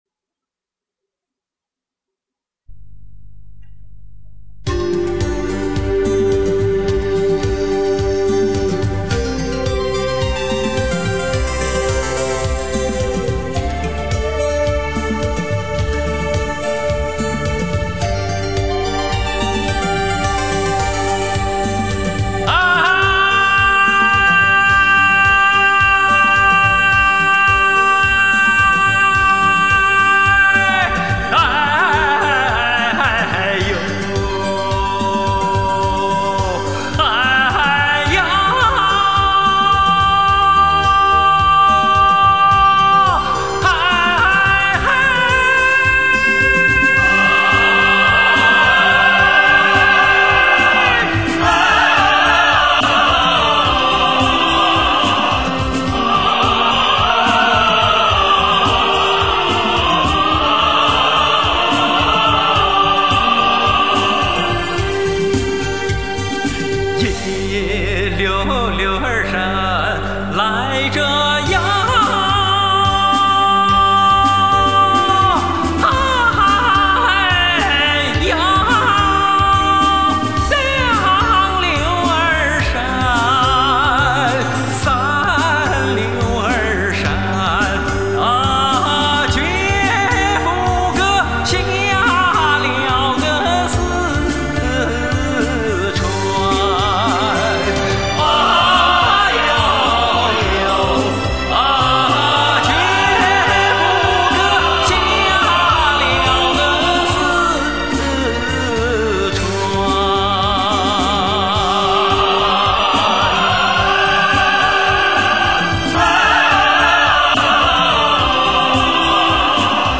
趁着上山的热乎劲，再给大家唱一曲土土的民歌吧。。